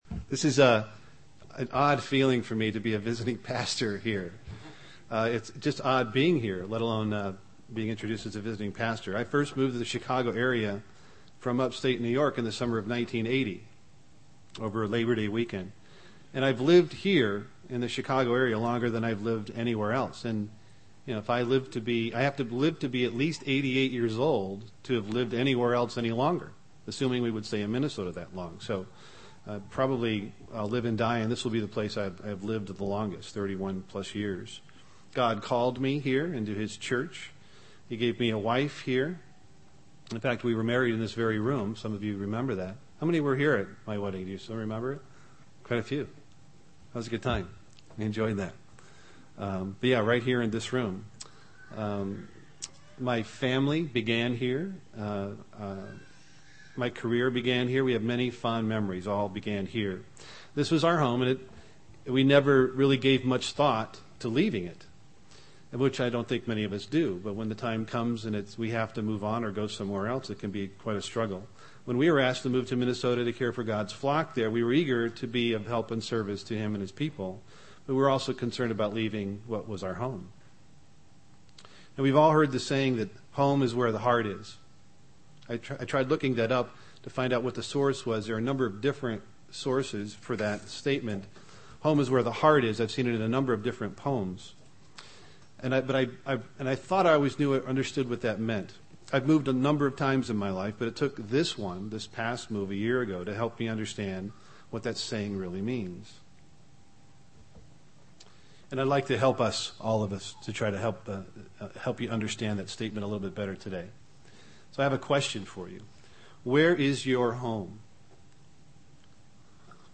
Given in Chicago, IL
UCG Sermon Studying the bible?